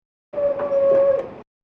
These sounds are primarily industrial / noise type sounds.
The following is a series of very short samples.
sound 3 1 sec. mono 18k